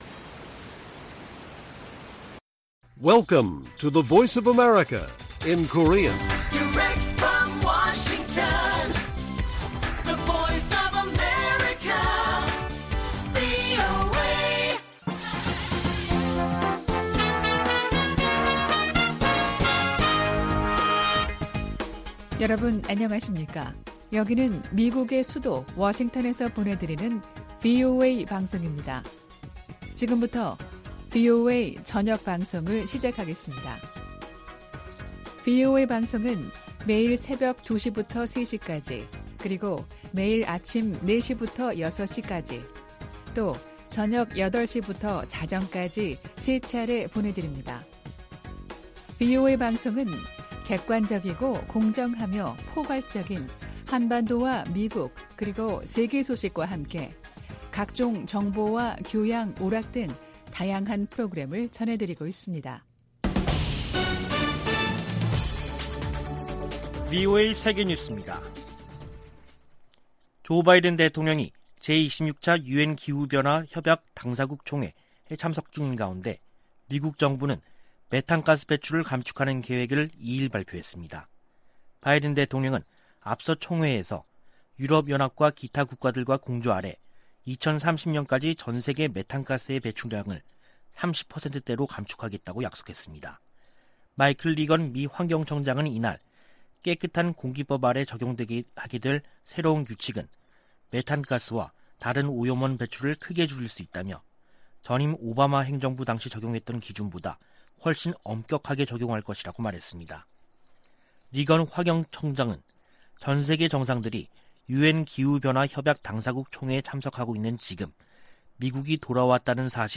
VOA 한국어 간판 뉴스 프로그램 '뉴스 투데이', 2021년 11월 2일 1부 방송입니다. 중국과 러시아가 유엔 안전보장이사회에 대북제재 완화를 위한 결의안 초안을 다시 제출했습니다.